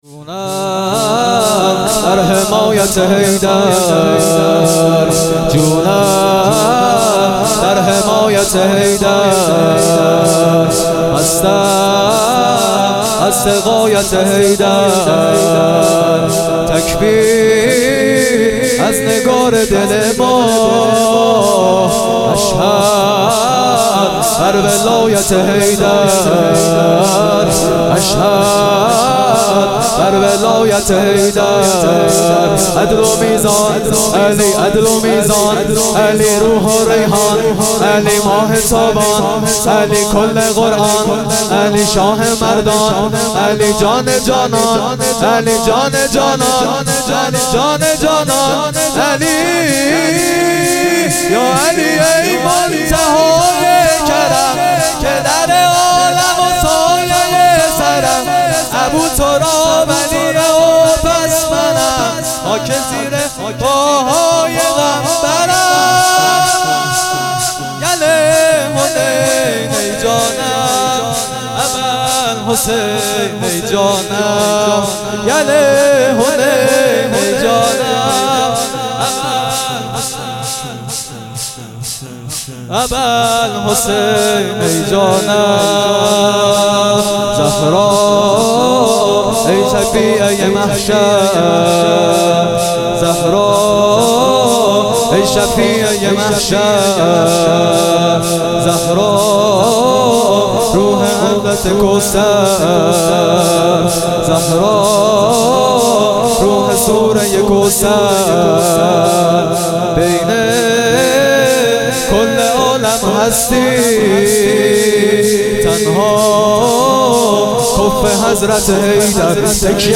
شور _جونم در حمایت حیدر